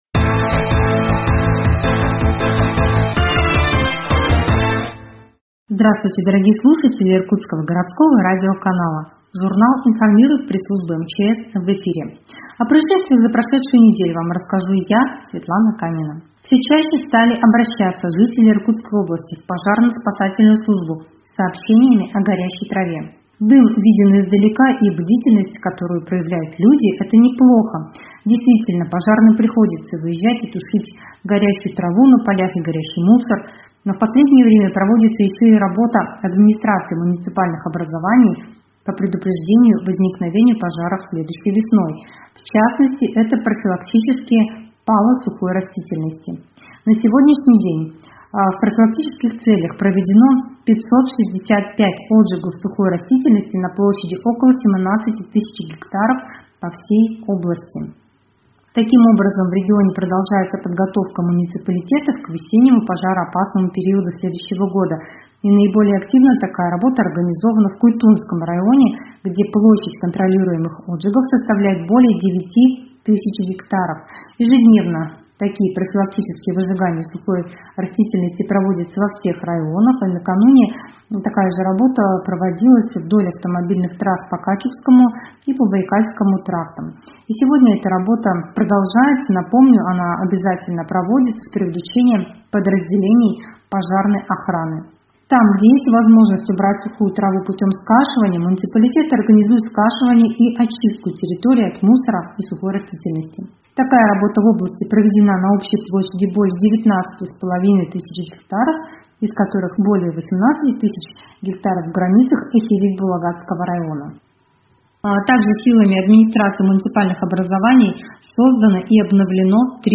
Радиожурнал «Информирует МЧС»: Пожары и поведение на льду 29.10.2021